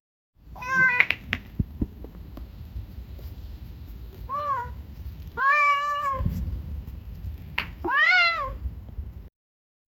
make a sound of cats clapping paws nonstop
make-a-sound-of-cats-udpyl7vc.wav